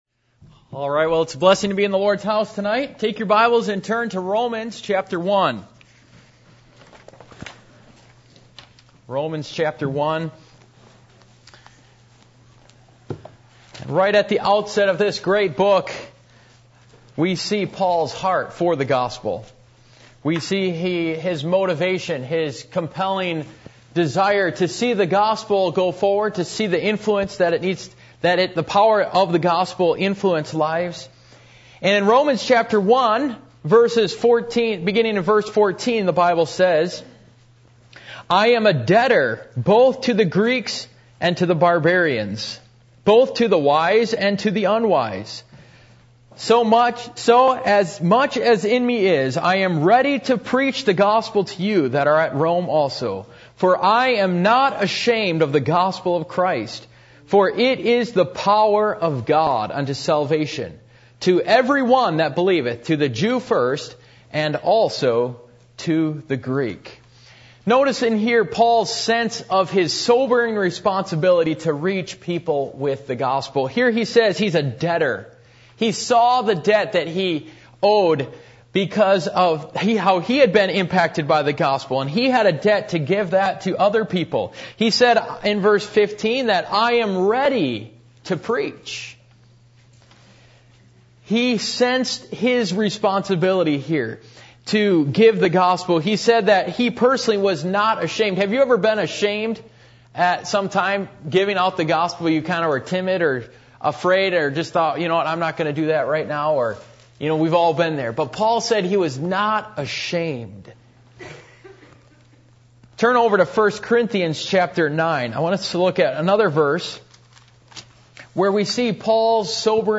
Passage: Romans 1:16 Service Type: Midweek Meeting %todo_render% « The Judgment That Is Coming